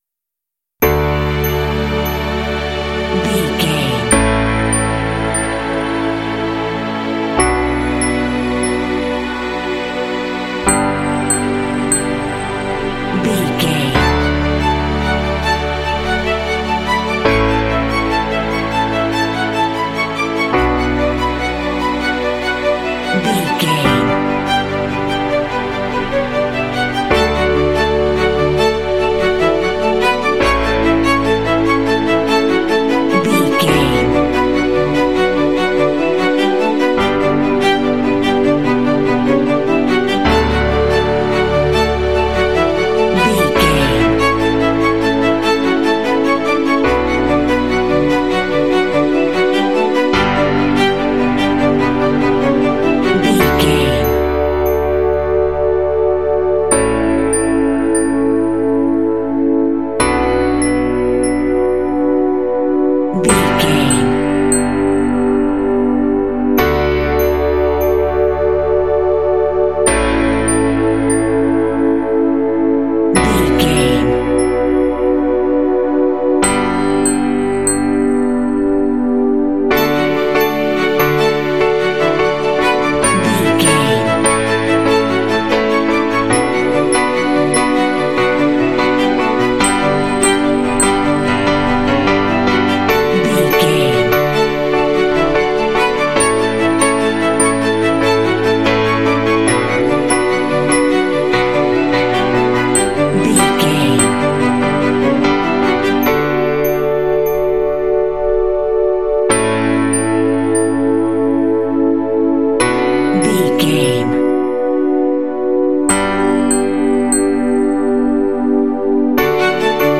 Uplifting
Ionian/Major
D
energetic
bouncy
piano